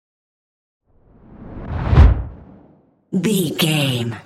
Dramatic whoosh deep trailer
Sound Effects
Atonal
dark
intense
whoosh